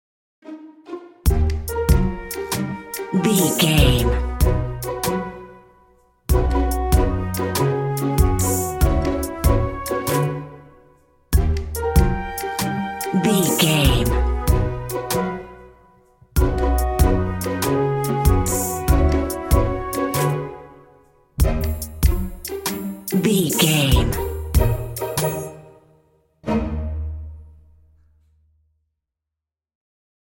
Ionian/Major
orchestra
strings
percussion
flute
silly
circus
goofy
comical
cheerful
perky
Light hearted
quirky